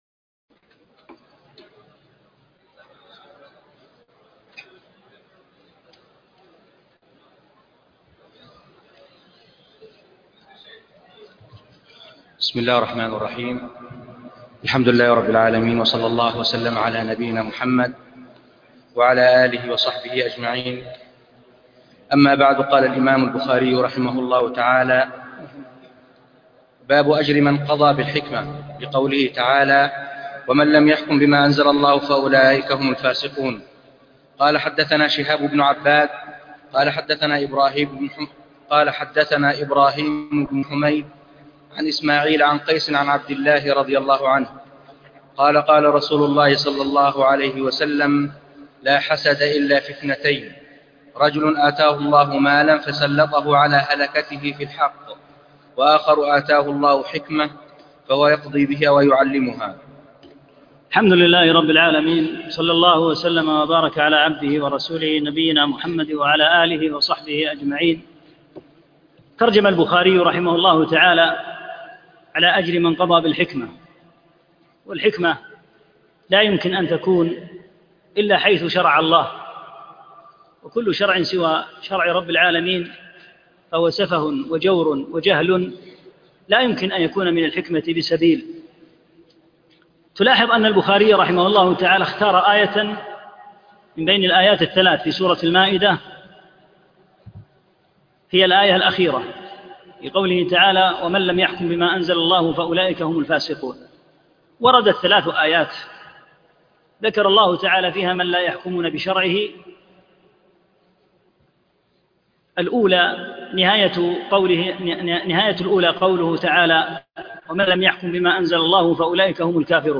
2- الدرس الثاني